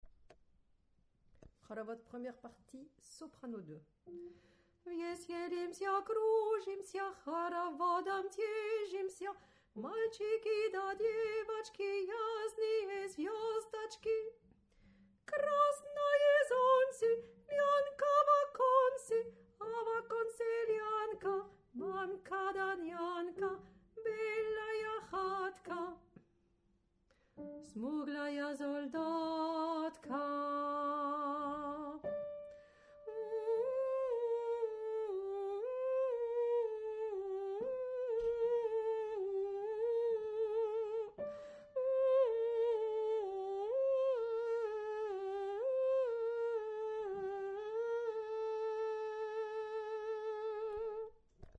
Soprano2